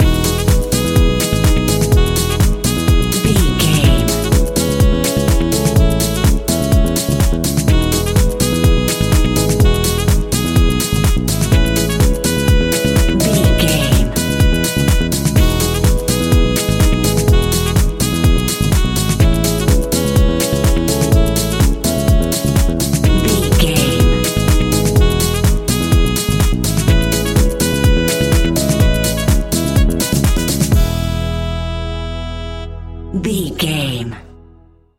Ionian/Major
uplifting
energetic
bouncy
bass guitar
saxophone
piano
electric piano
drum machine
nu disco
groovy
upbeat